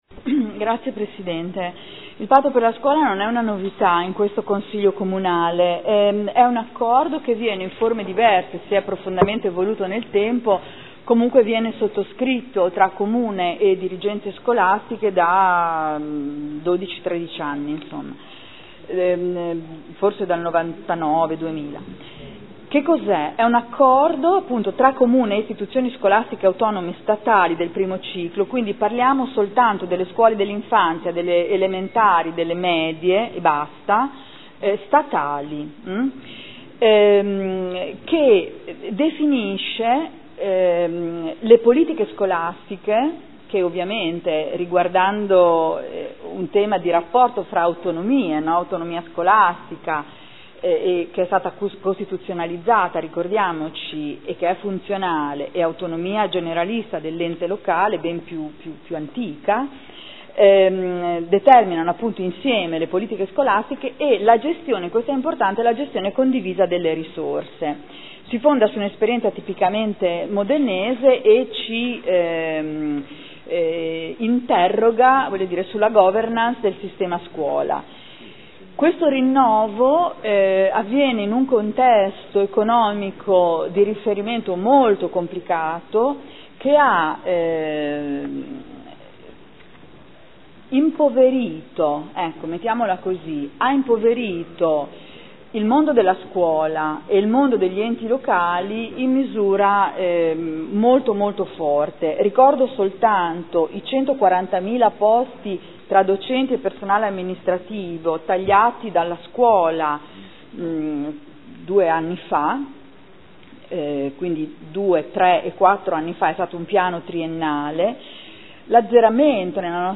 Seduta del 12 settembre 2013